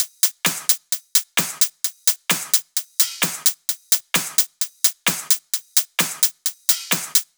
VFH2 130BPM Comboocha Kit 9.wav